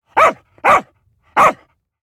dog_bark_angry.ogg